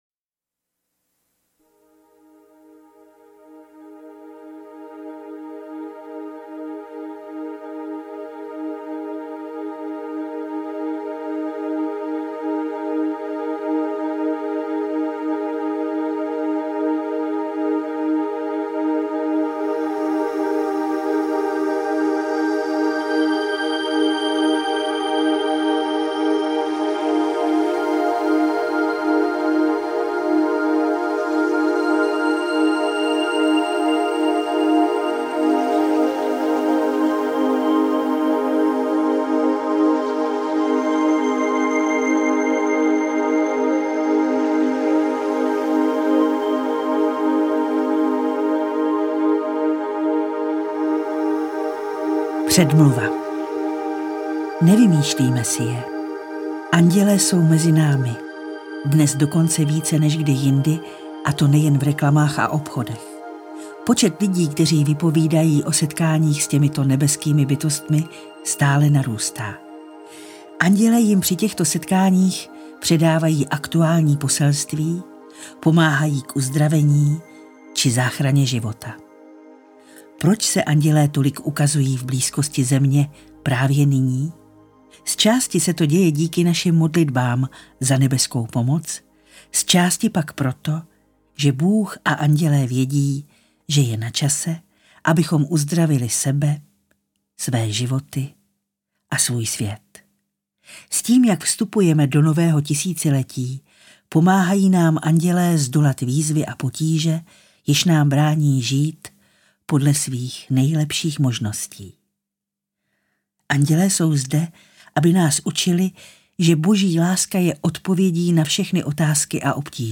Interpret:  Naďa Konvalinková
Audio verze bestselleru Doreen Virtue včetně meditace. Esoterické, tak praktické metody, jak s pomocí andělů léčit vaše vztahy, tělo, profesi či rodinný život.